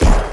PlayerShot.wav